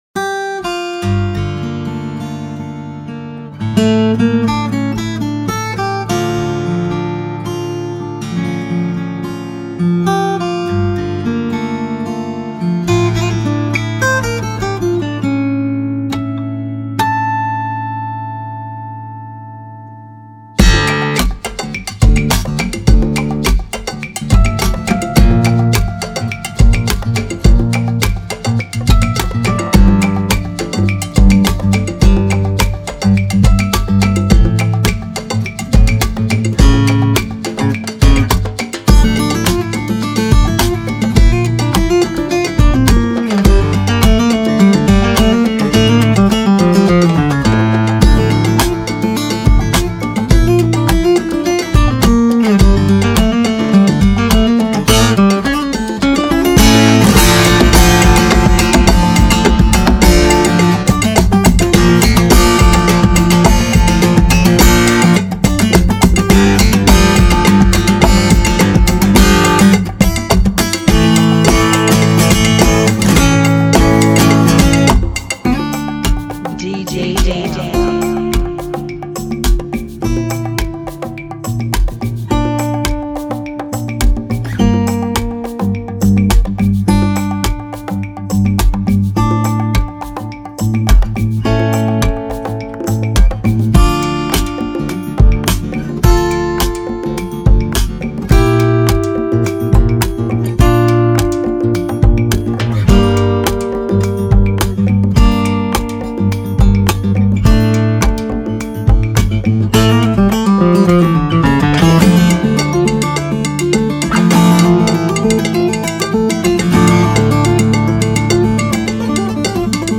105 BPM
Genre: Salsa Remix